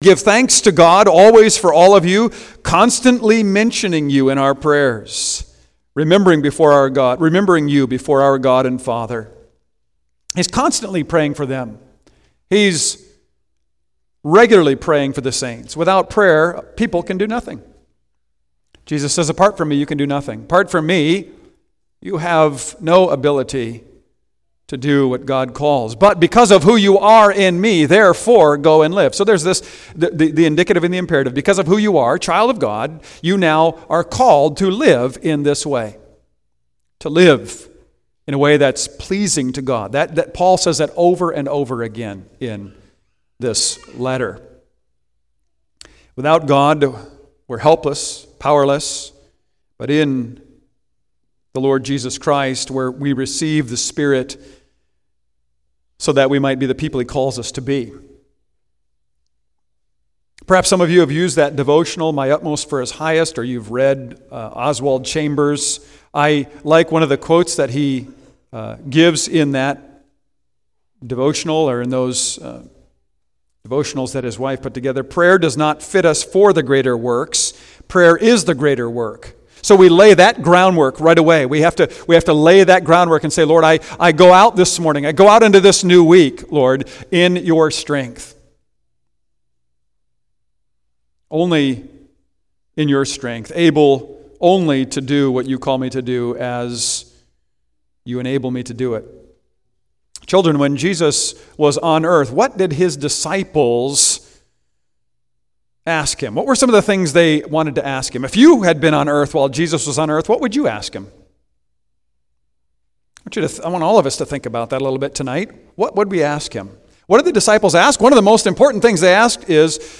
Passage: I Thessalonians 5:25-28 Service Type: Evening